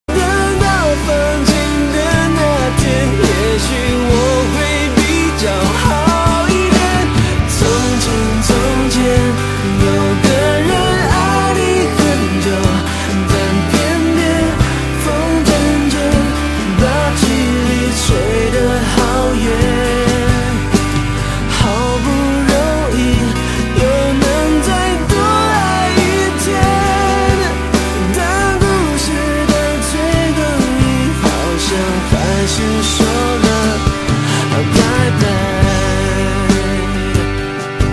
枪声与歌曲完美结合